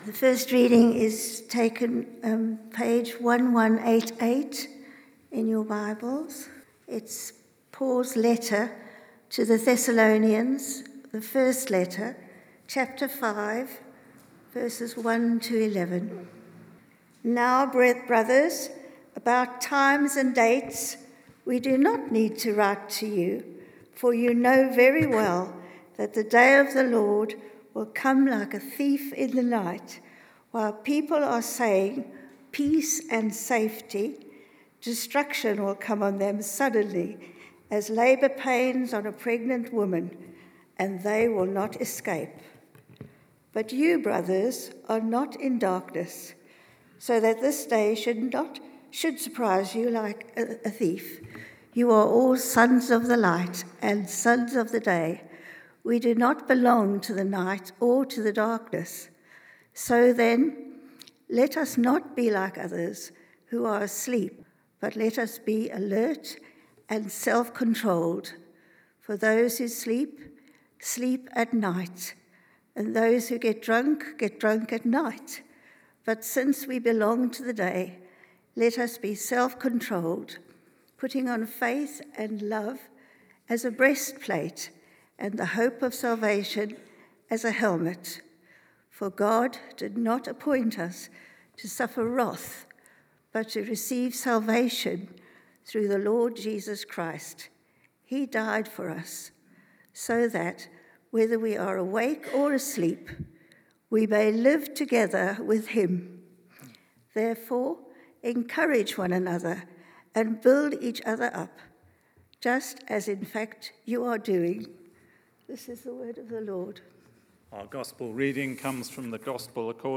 Emmanuel Church Sermons